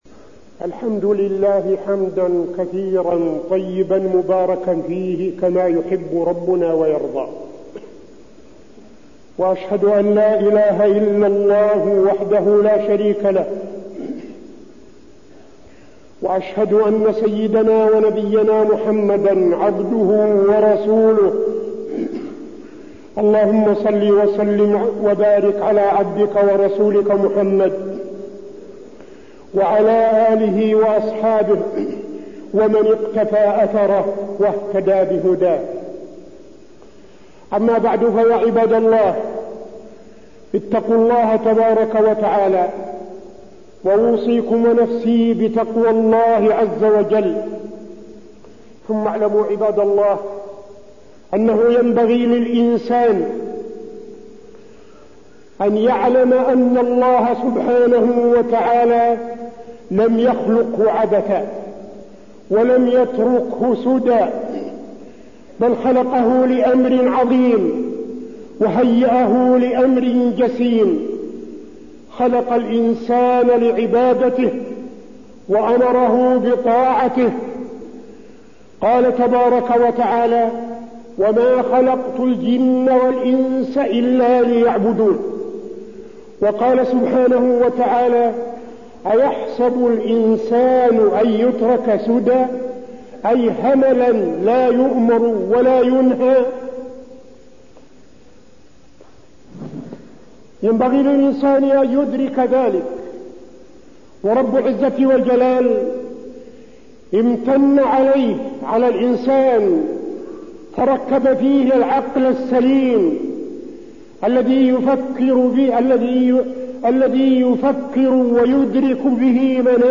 تاريخ النشر ١ جمادى الآخرة ١٤٠٧ هـ المكان: المسجد النبوي الشيخ: فضيلة الشيخ عبدالعزيز بن صالح فضيلة الشيخ عبدالعزيز بن صالح آثار شرب الخمر